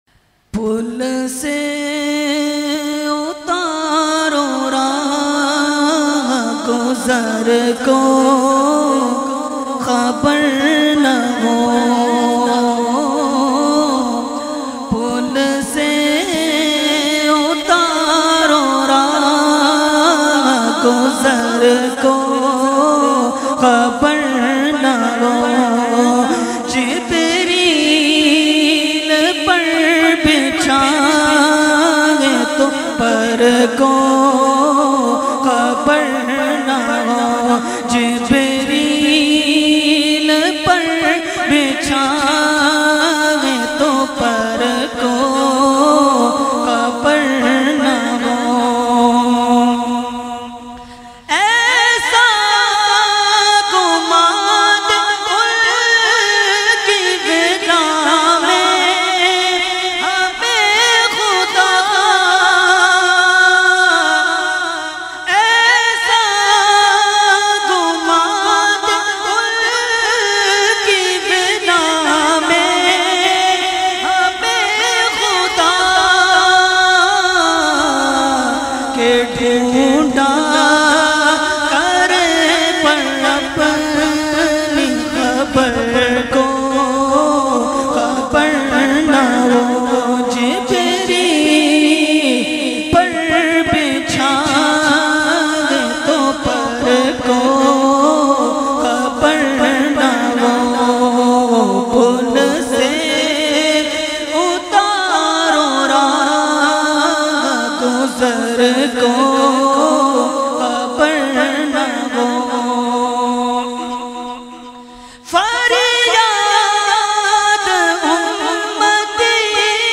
Category : Naat | Language : UrduEvent : Muharram 2020